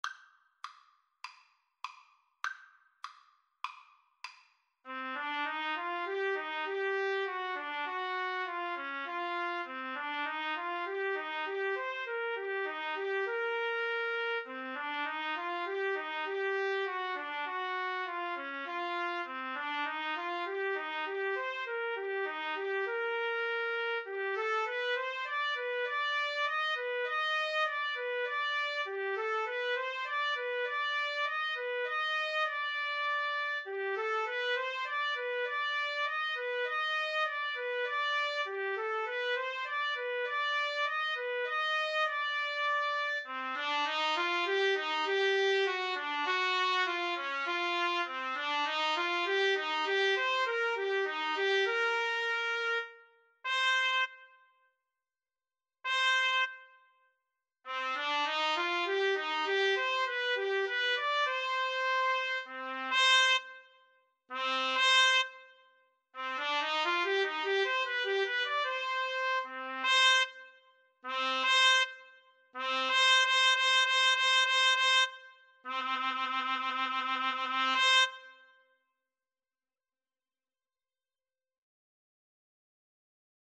4/4 (View more 4/4 Music)
C minor (Sounding Pitch) D minor (Trumpet in Bb) (View more C minor Music for Trumpet-Trombone Duet )
Trumpet-Trombone Duet  (View more Intermediate Trumpet-Trombone Duet Music)
Classical (View more Classical Trumpet-Trombone Duet Music)